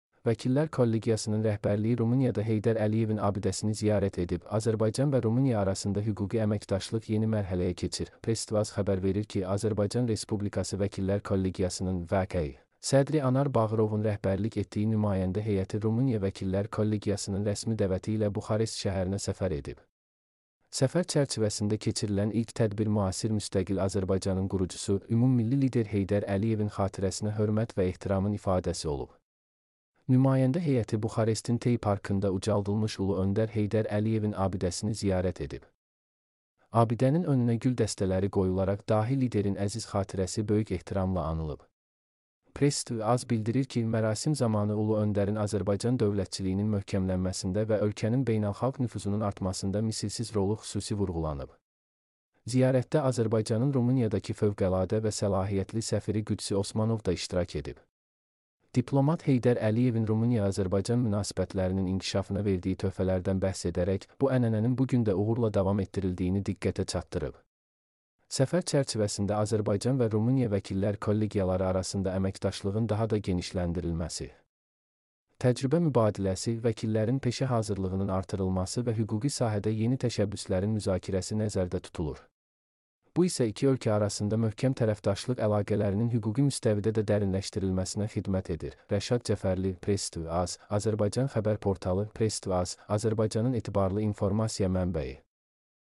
mp3-output-ttsfreedotcom-32.mp3